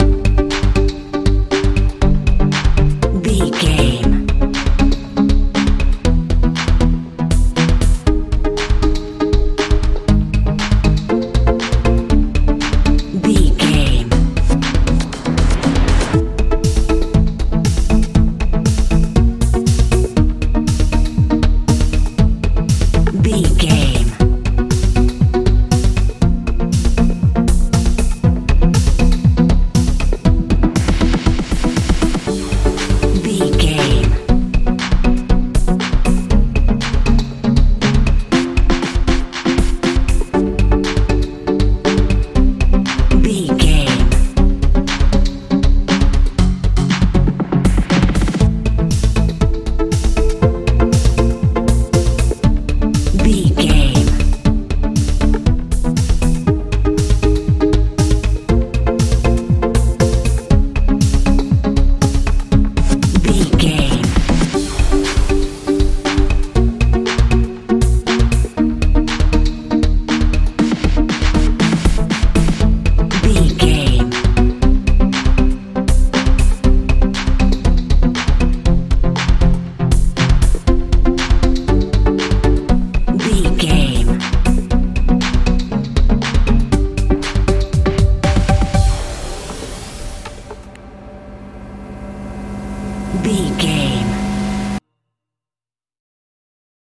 techno dance feel
Ionian/Major
strange
bouncy
cool
synthesiser
bass guitar
drums
90s
80s